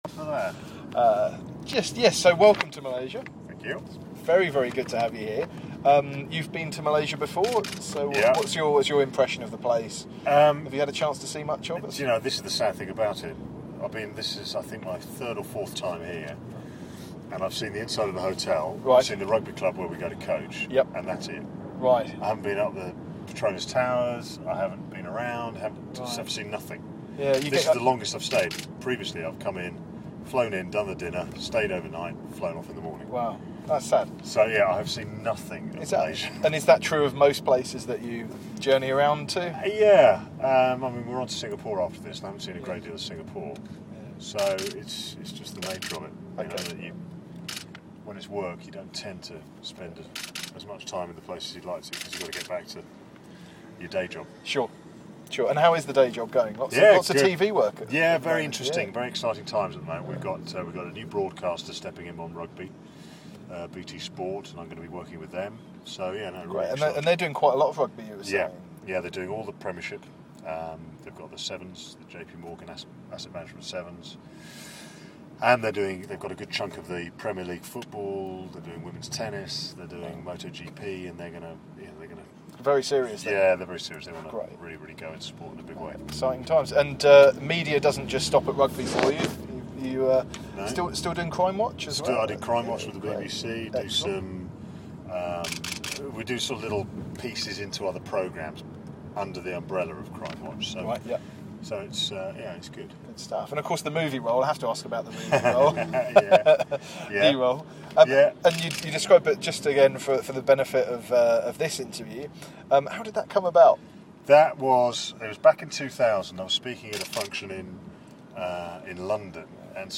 Martin Bayfield talks to Expatriate Lifestyle about his rugby career and his impressions of Malaysia.